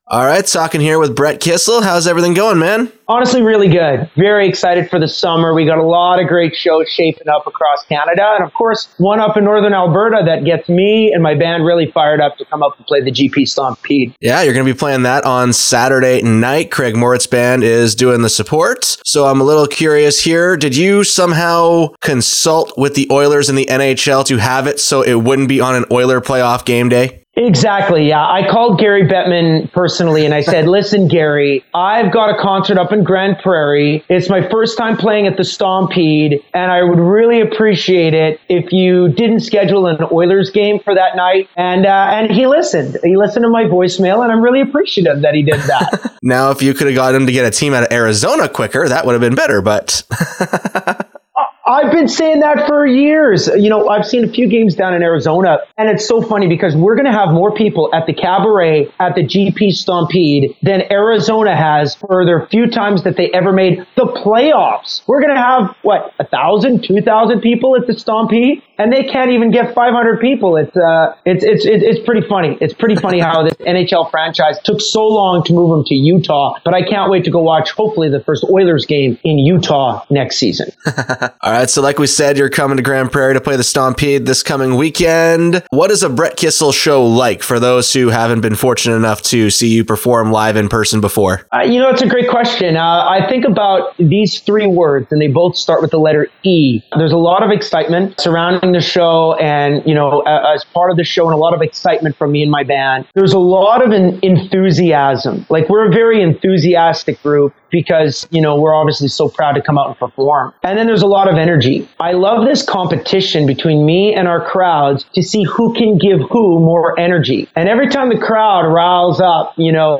Conversation With Brett Kissel